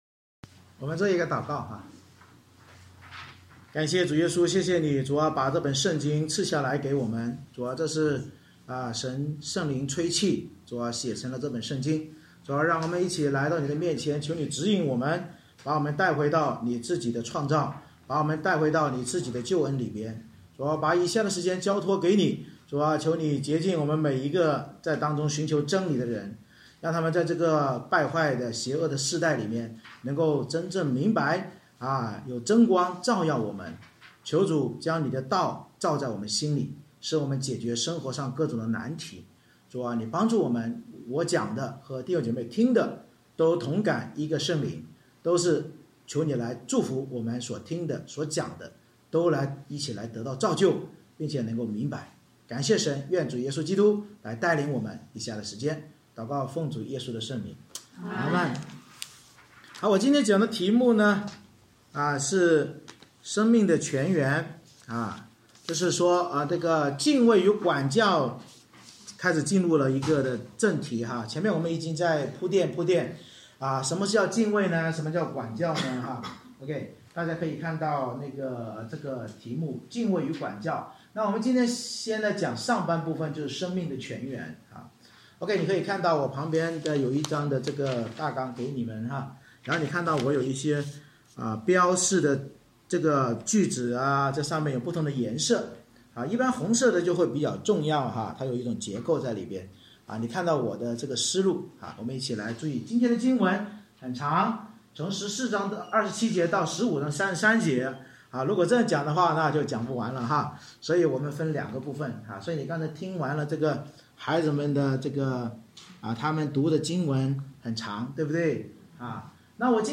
箴言14:27-15:5 Service Type: 主日崇拜 所罗门箴言教导敬畏与管教，使我们认识主的眼目无处不在，从君王到穷人祂都鉴察，只有敬畏主才能真正认识永生之源并远离死亡网罗。